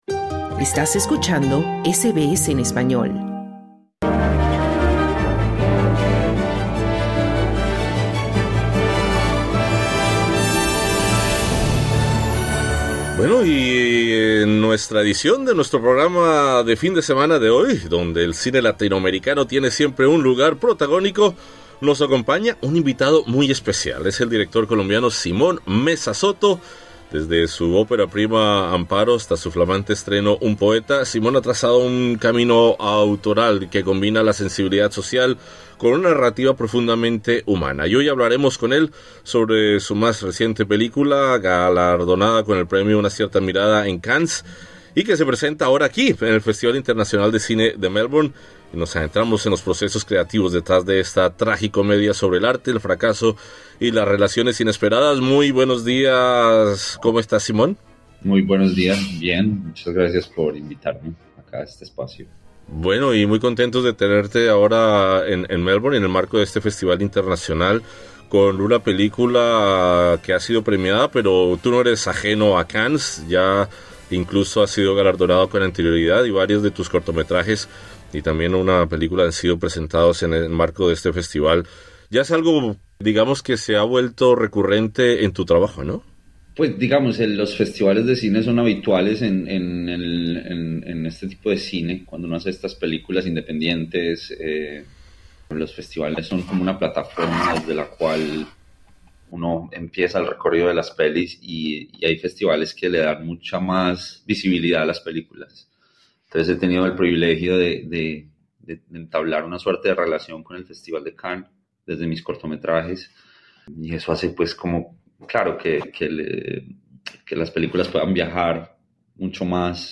El director colombiano Simón Mesa Soto nos habla sobre Un Poeta, su nueva película estrenada en Cannes y ahora presentada en el Festival Internacional de Cine de Melbourne (MIFF).